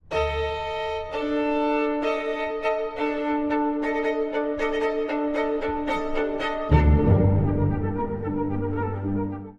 続いて登場するのが、半音下げたE線のソロバイオリン
これは、死の舞踏そのもの。不安定で不気味な音色によって、聴き手を非日常の世界へと誘います。
第1主題は、骸骨たちが踊る軽快なジグ（6/8拍子）の旋律。
フルートによって提示され、その後さまざまな楽器に受け継がれていきます。